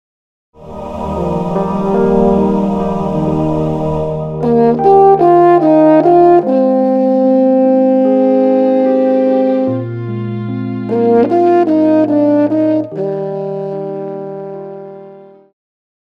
Pop
French Horn
Band
POP,Classical Rearrangement
Instrumental
Ballad
Only backing